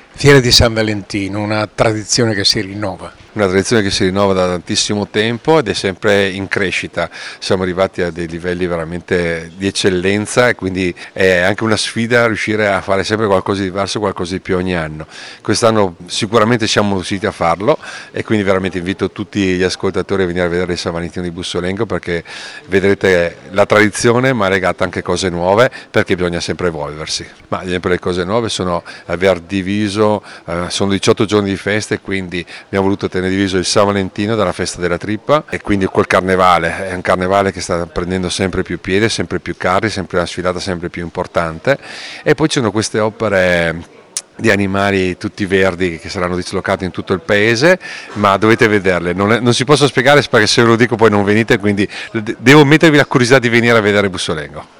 Roberto Brizzi, sindaco di Bussolengo
Roberto-Brizzi-sindaco-di-Bussolengo.wav